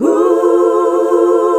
HUH SET F.wav